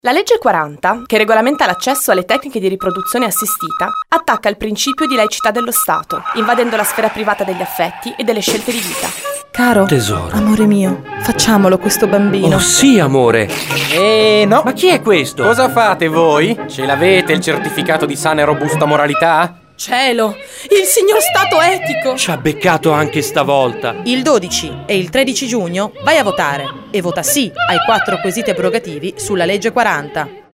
SPOT 3 | stato etico